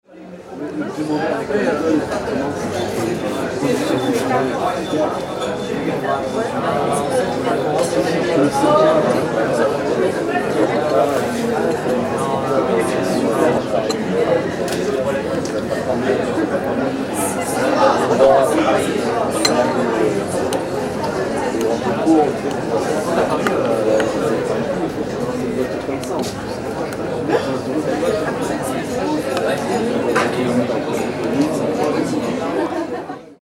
Gemafreie Sounds: Gastronomie
mf_SE-4375-street_cafe_in_paris.mp3